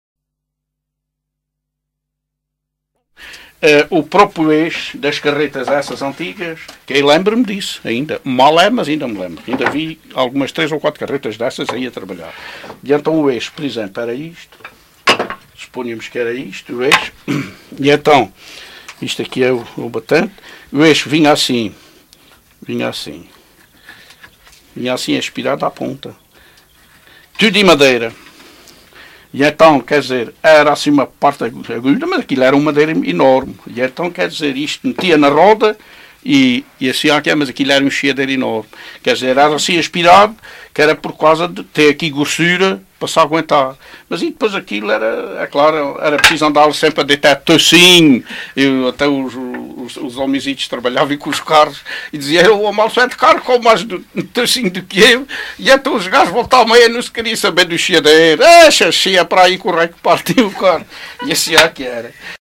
LocalidadeCastelo de Vide (Castelo de Vide, Portalegre)